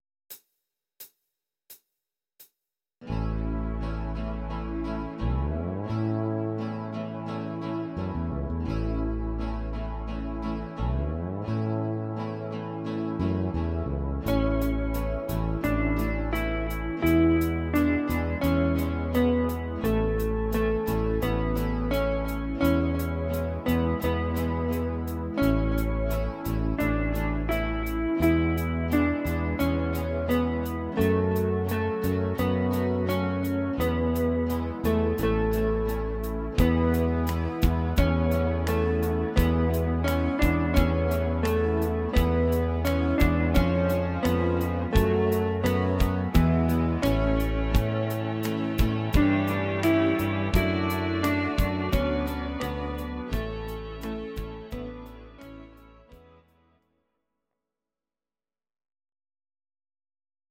These are MP3 versions of our MIDI file catalogue.
Please note: no vocals and no karaoke included.
Gitarre